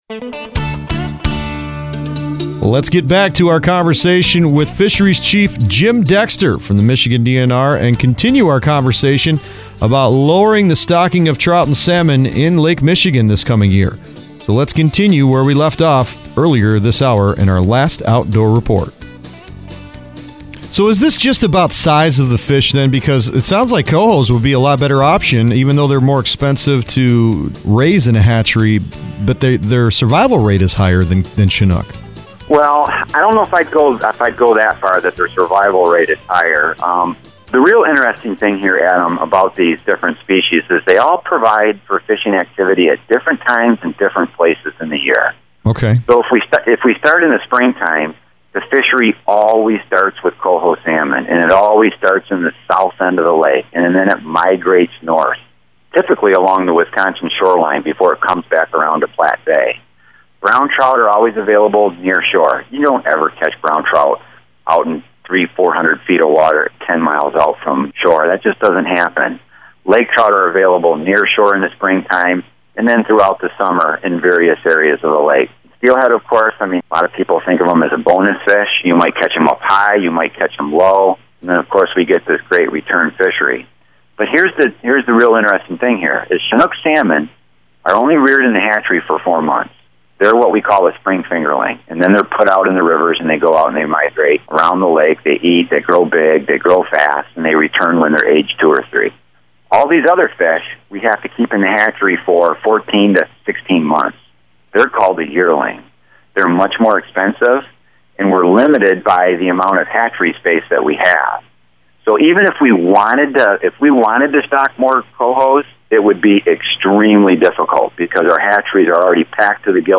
Marquette, MichiganOctober 25, 2016 – We talked with Michigan DNR Fisheries Chief Jim Dexter throughout today’s show.  We talked about declining salmon and trout returns, alewives, and invasive mussels causing this whole problem.